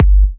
edm-kick-61.wav